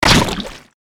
AA_throw_wedding_cake_cog.ogg